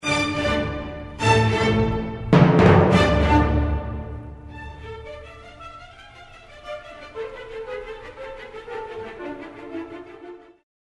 powerful